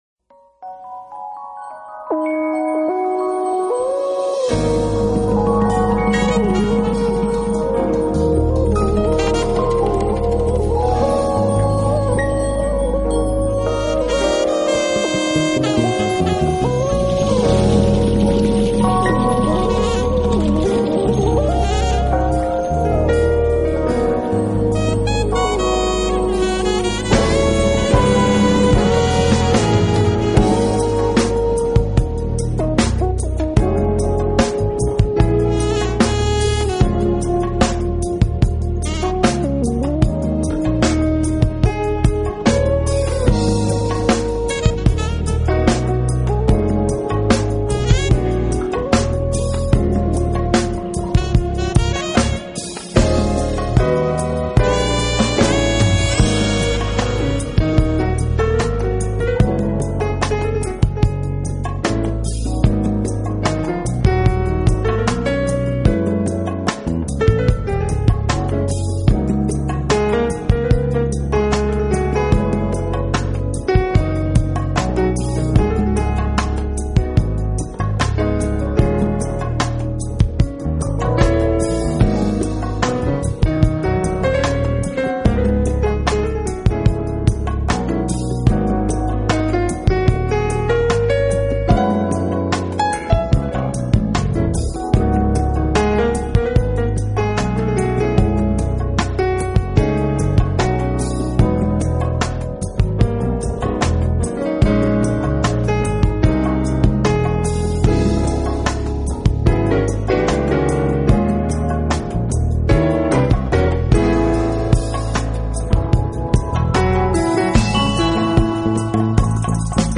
This soulful jazz excursion